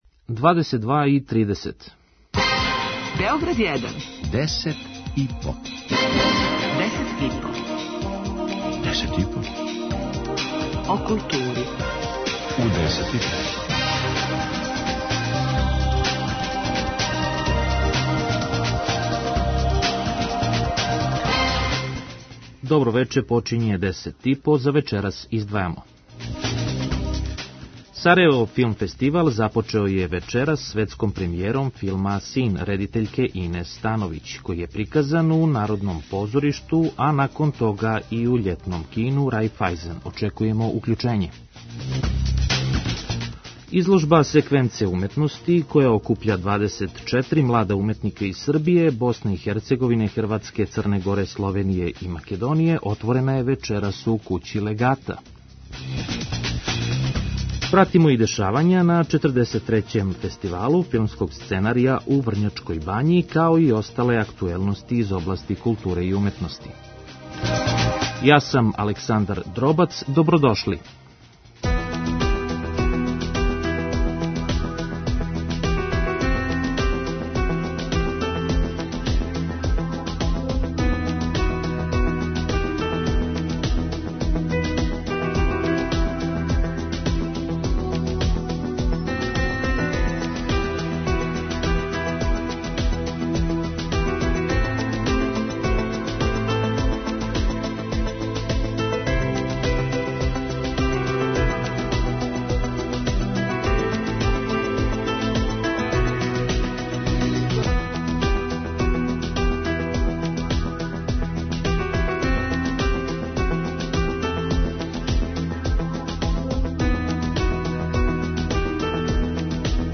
преузми : 5.37 MB Десет и по Autor: Тим аутора Дневни информативни магазин из културе и уметности. Вести, извештаји, гости, представљање нових књига, концерата, фестивала, репортерска јављања са изложби, позоришних и филмских премијера и најактуелнијих културних догађаја.